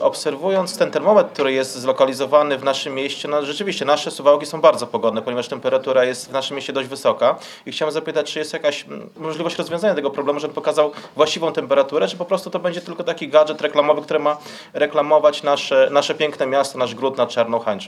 Okazało się, że w słoneczne dni hucznie odsłonięty termometr, który miał być atrakcją Suwałk, wskazuje temperaturę dużo wyższą niż rzeczywista.  Sprawę podniósł na ostatniej sesji Rady Miejskiej Karol Korneluk, radny Sojuszu Lewicy Demokratycznej.